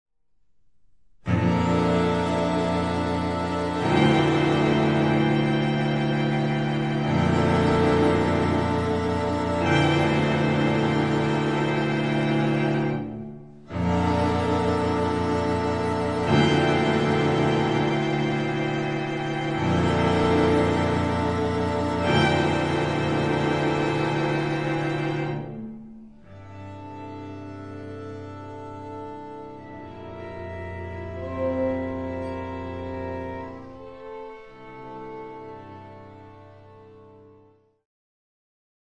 String Quartet No. 6